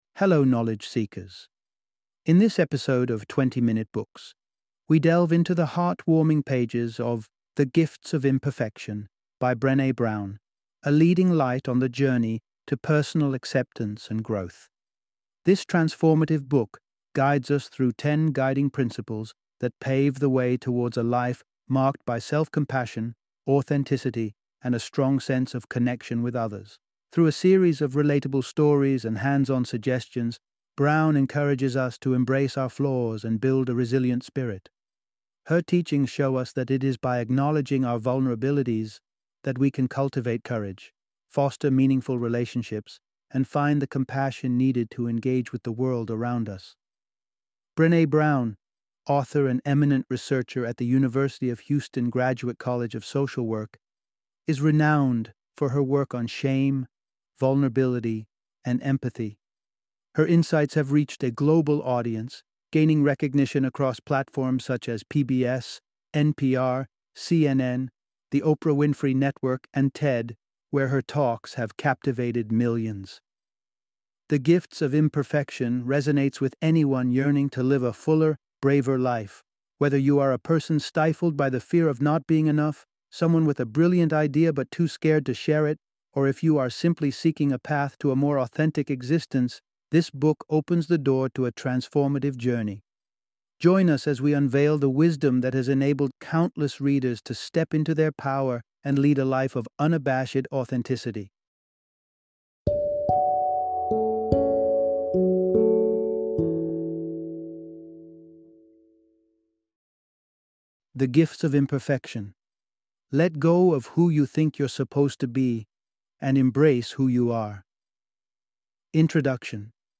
The Gifts of Imperfection - Audiobook Summary